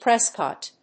/ˈprɛskɑt(米国英語), ˈpreskɑ:t(英国英語)/